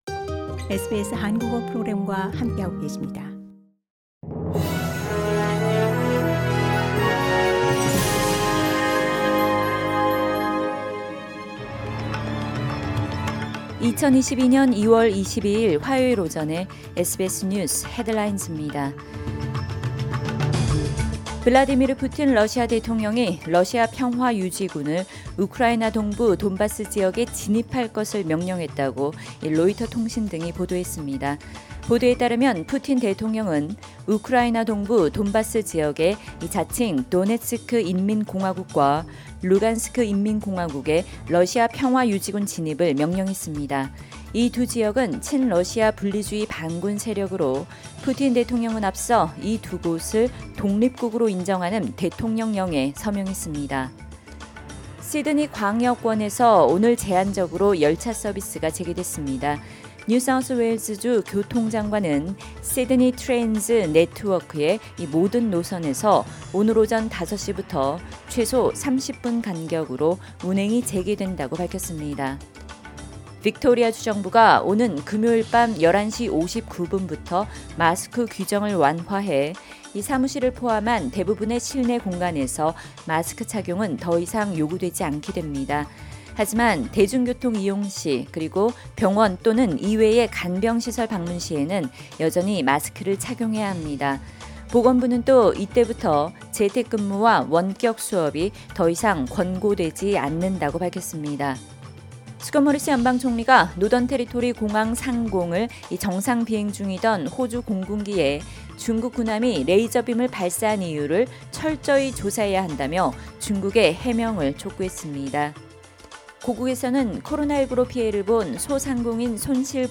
SBS News Headlines…2022년 2월 22일 화요일 오전 뉴스